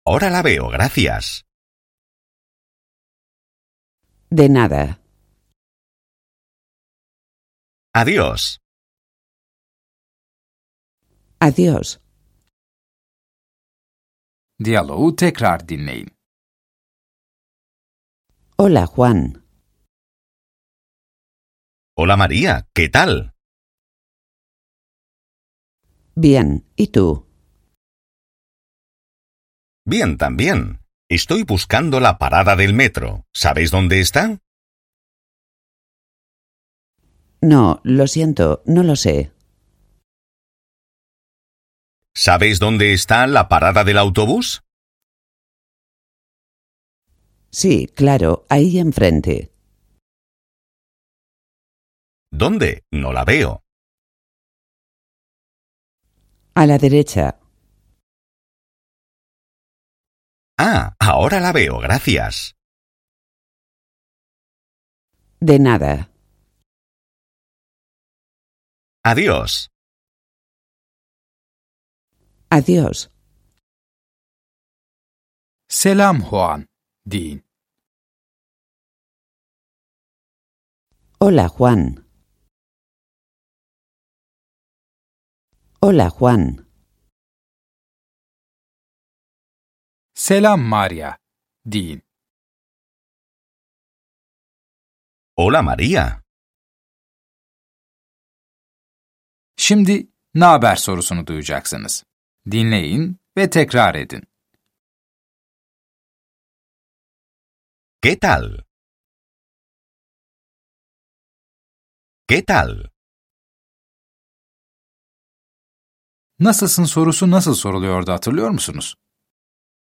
Dersler boyunca sizi yönlendiren bir kişisel eğitmeniniz olacak. Ana dili İspanyolca olan iki kişi de sürekli diyalog halinde olacaklar. Yönlendirmeniniz size söz verdikçe gerekli tekrarları yapın ve sorulan sorulara cevap verin.